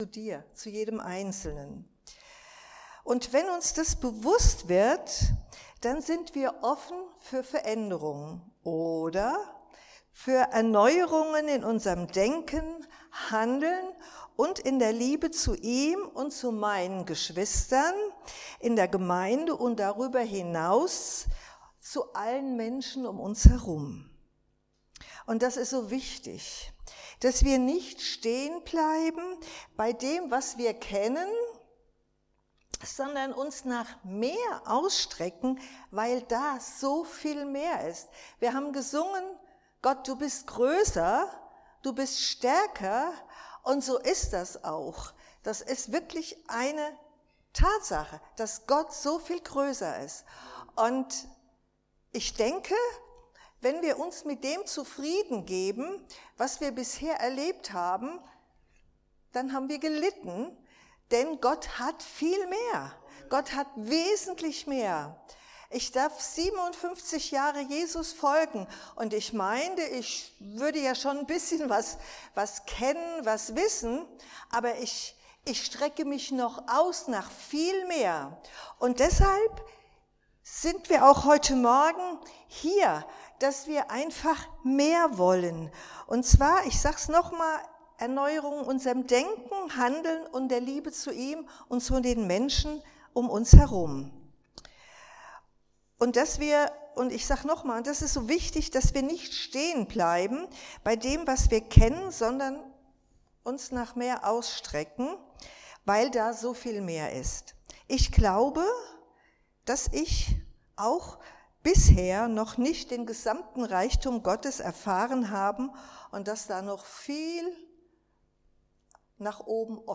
Dienstart: Predigt